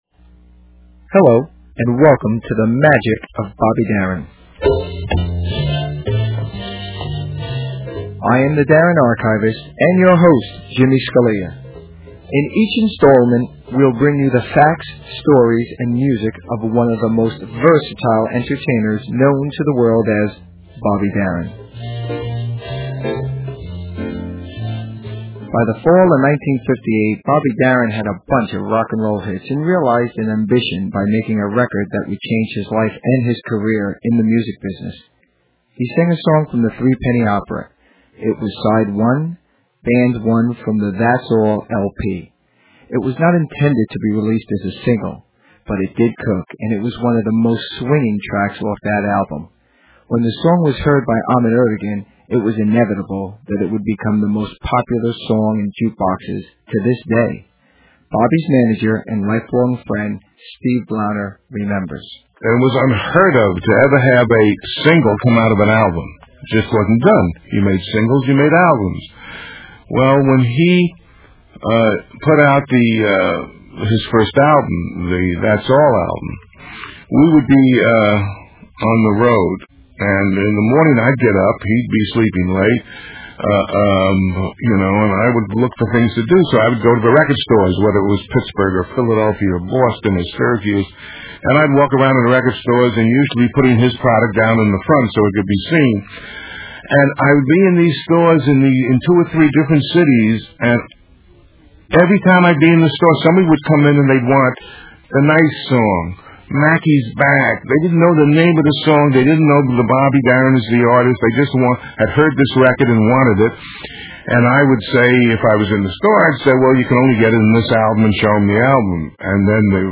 Technical Note: Please keep in mind that due to time and space contraint on the internet, and legal worries of sharing too much, the music portions have been edited and the quality is subpar.